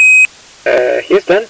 fail_astro.mp3